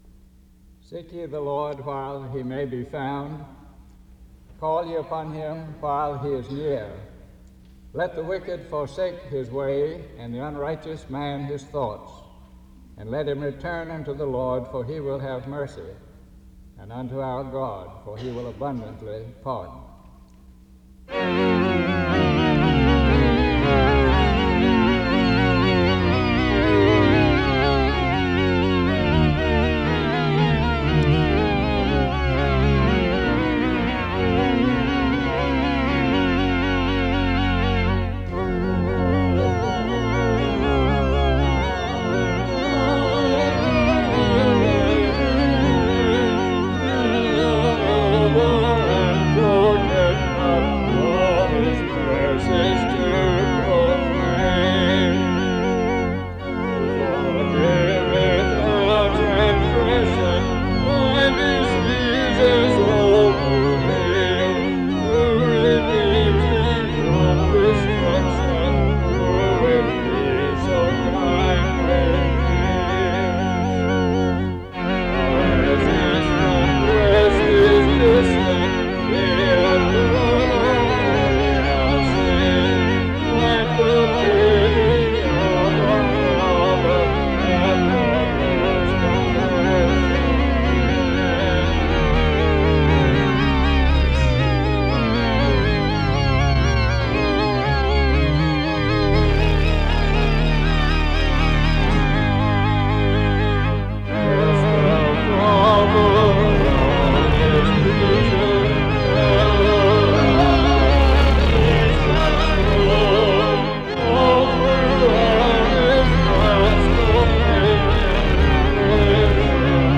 Location Wake Forest (N.C.)
SEBTS Chapel and Special Event Recordings